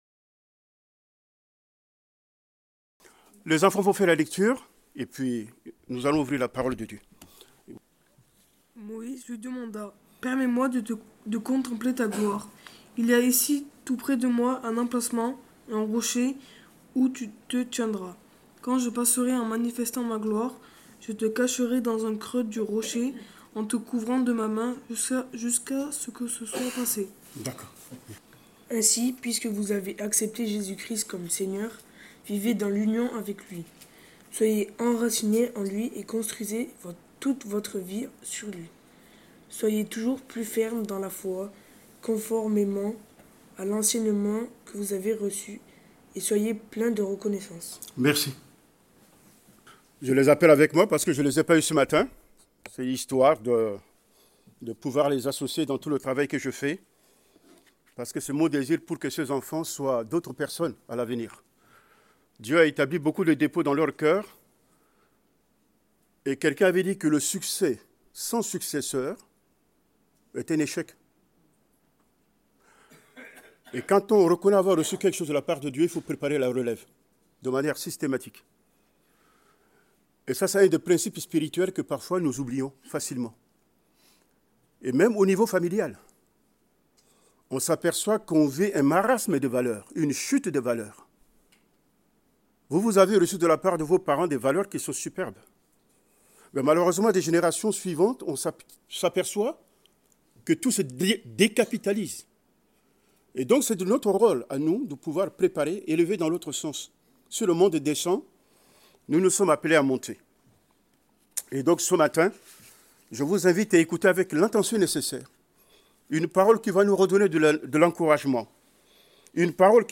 Prédication du 25 janvier 2026.